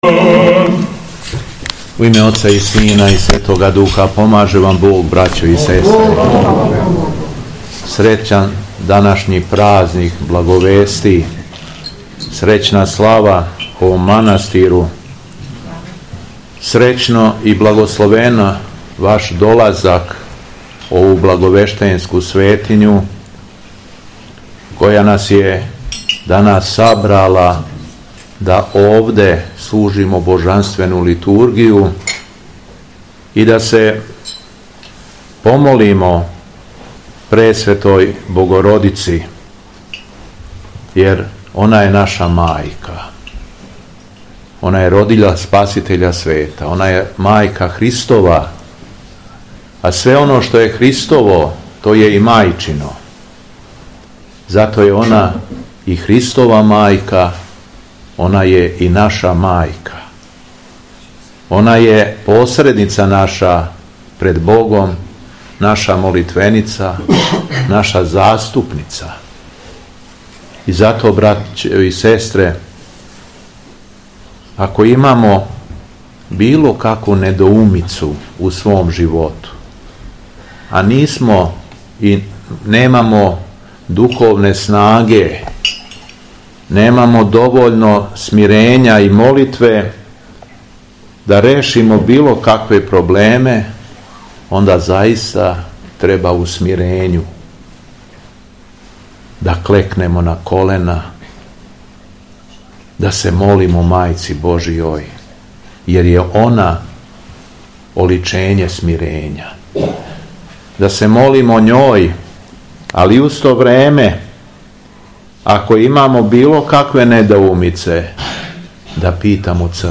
ПРАЗНИК БЛАГОВЕСТИ У МАНАСТИРУ БЛАГОВЕШТЕЊЕ - Епархија Шумадијска
Беседа Његовог Високопреосвештенства Митрополита шумадијског г. Јована
Након прочитаног Јеванђеља кроз беседу верни народ поучио је владика Јован: